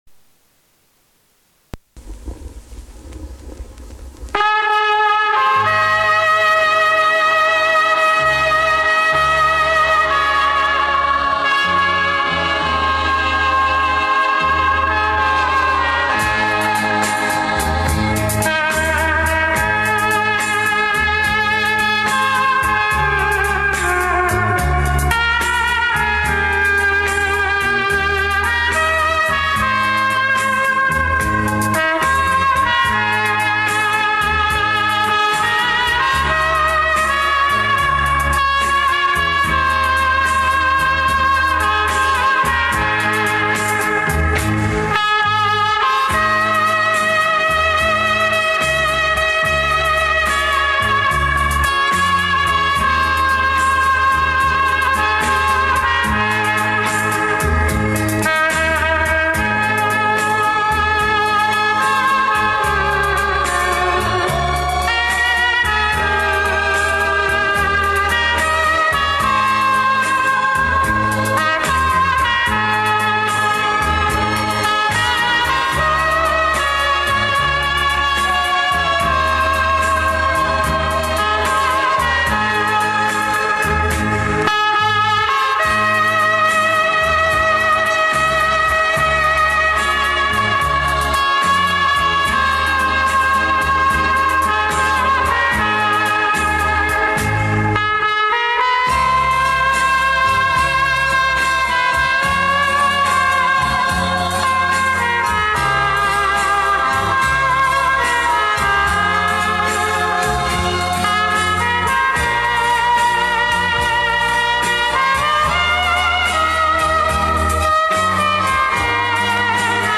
Trumpet